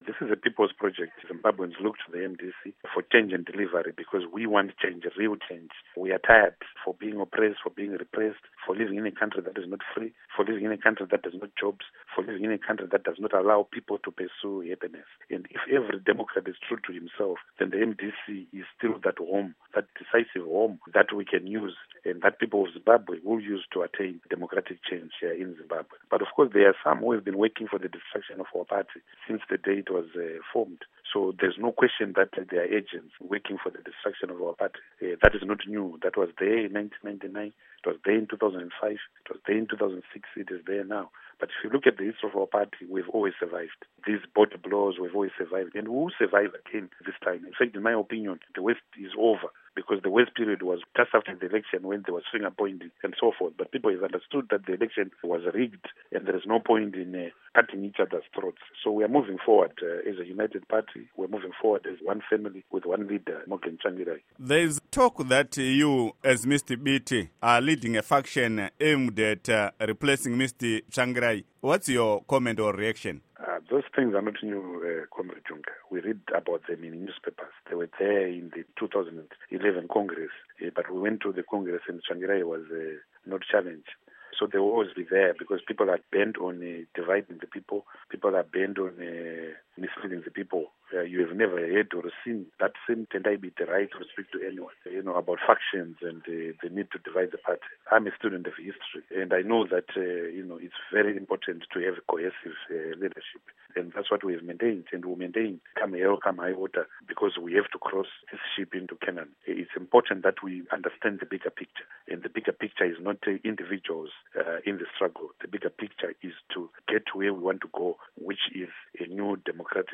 Interview With Tendai Biti